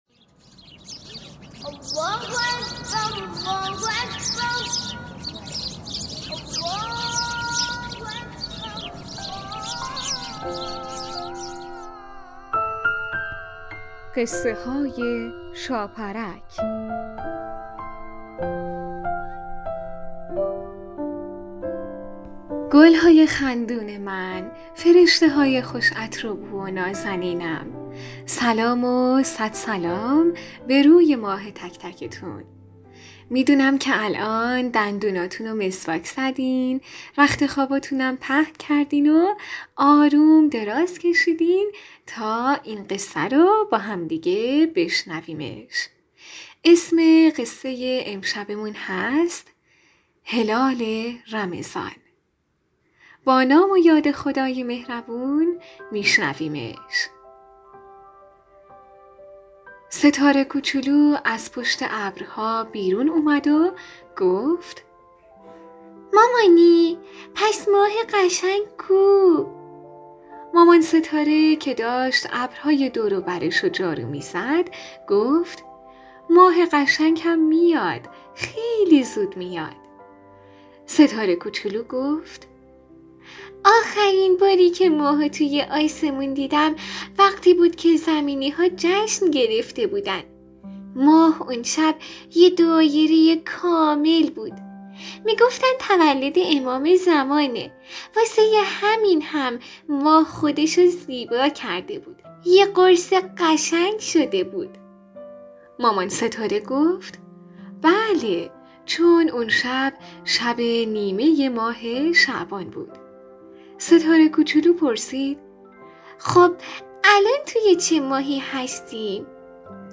قسمت صد و بیستم برنامه رادیویی قصه های شاپرک با نام هلال رمضان یک داستان کودکانه مذهبی با موضوعیت نماز است که ...